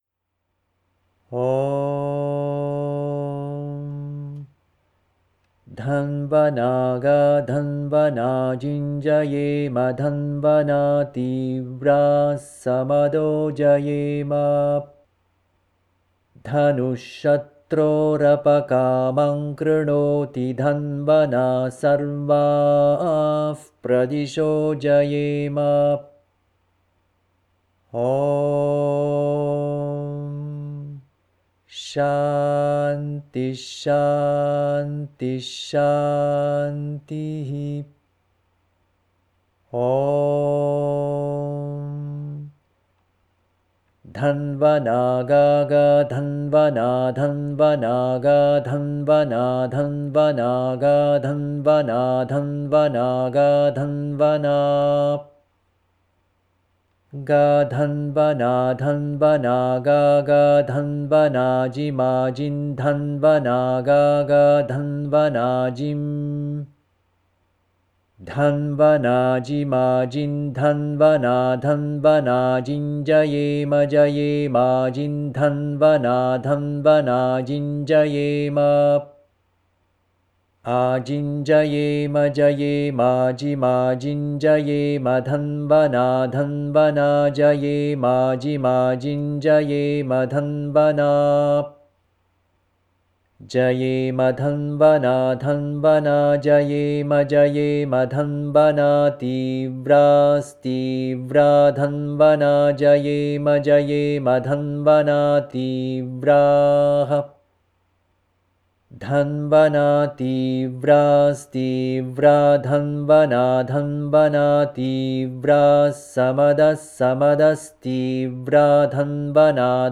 dhanvanaa gaa - ghana paaThaH - chant.mp3